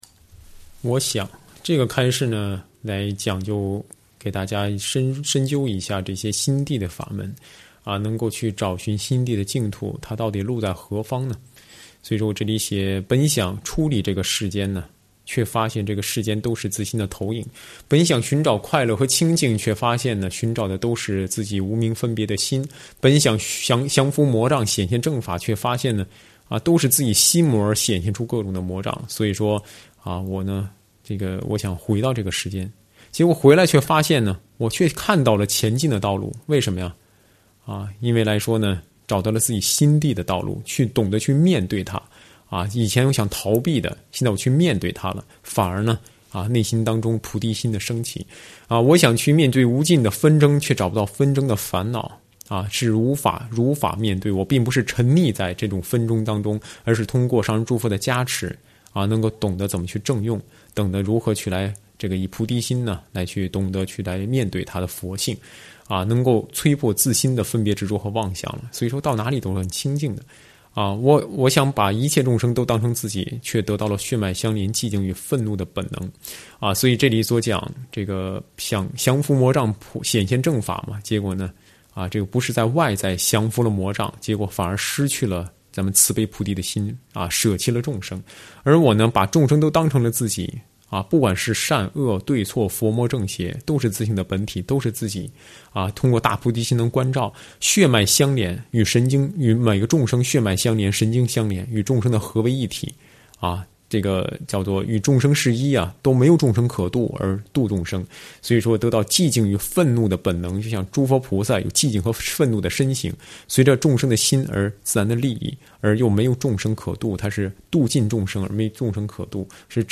随笔开示
上师语音开示